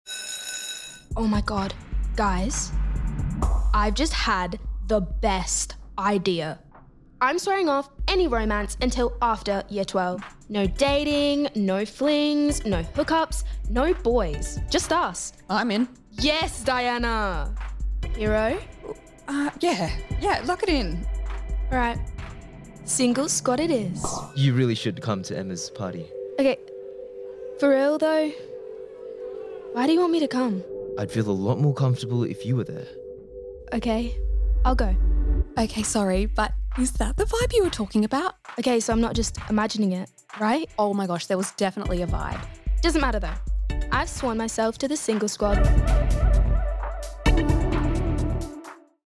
VOICE DEMO REEL